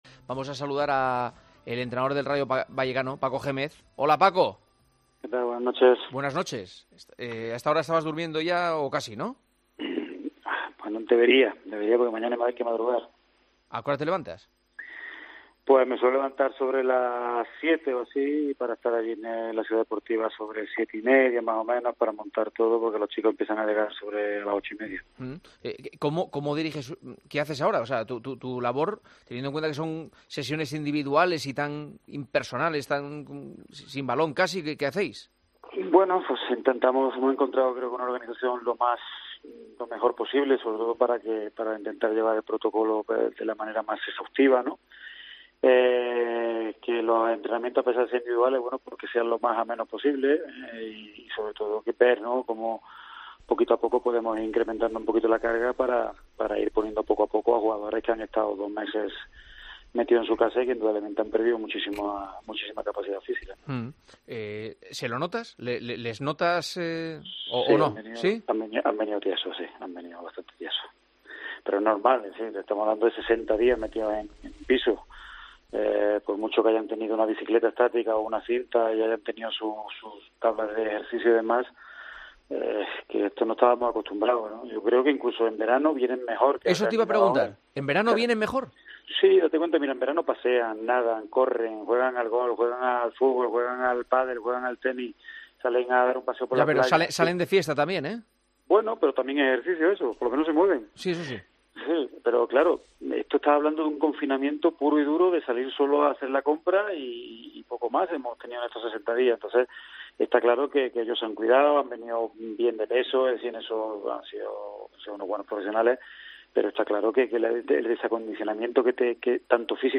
AUDIO: Hablamos con el entrenador del Rayo Vallecano en El Partidazo de COPE de la vuelta del fútbol y el peligro del contagio.